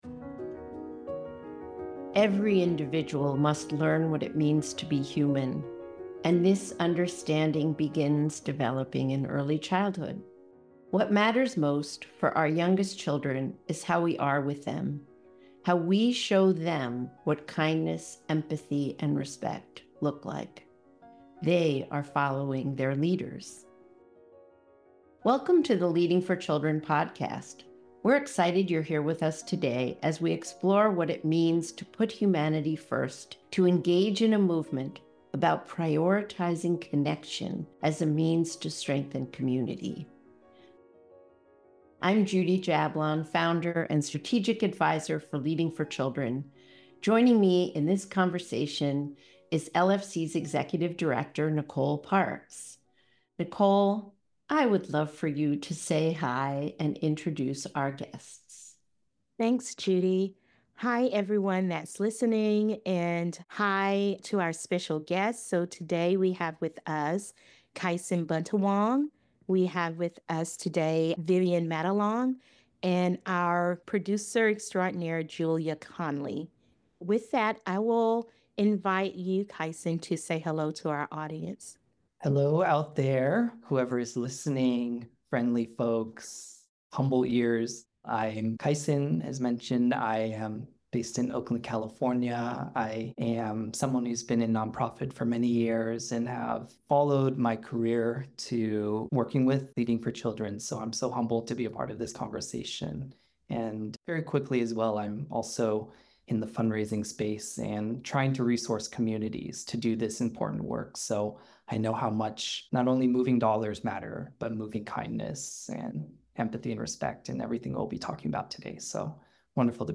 In Season 2 Episode 3 of The Leading for Children Podcast, the conversation explores how kindness, empathy, and respect serve as sources of strength in relationships and community life.